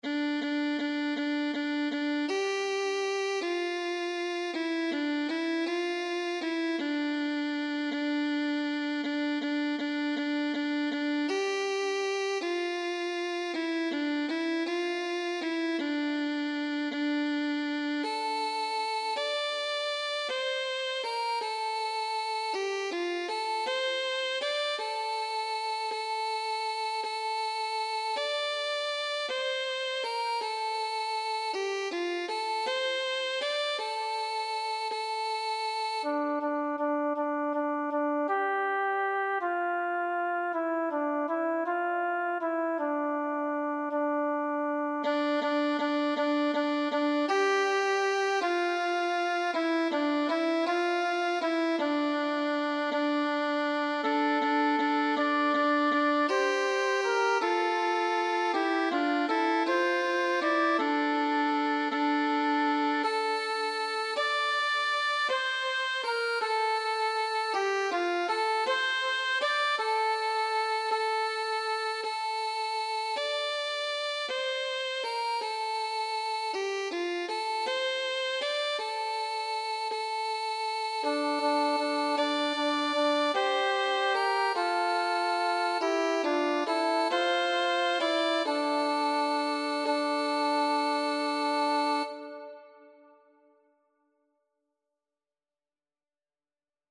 “Versión  original en canon- en hebreo y español„
Voz
Triángulo
Tambor